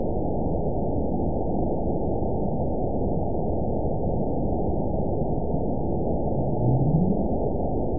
event 920325 date 03/16/24 time 18:18:36 GMT (1 year, 3 months ago) score 9.57 location TSS-AB03 detected by nrw target species NRW annotations +NRW Spectrogram: Frequency (kHz) vs. Time (s) audio not available .wav